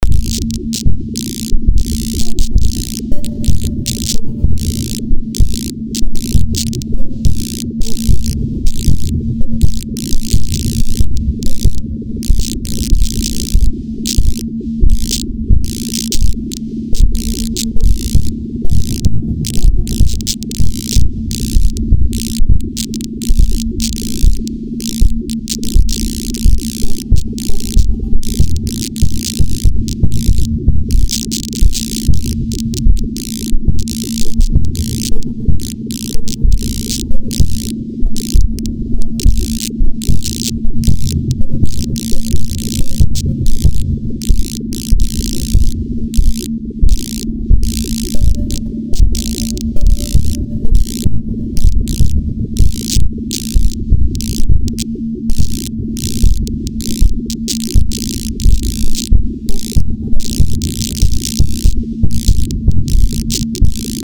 He e förresten morsekod.